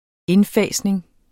Udtale [ ˈenˌfæˀsneŋ ]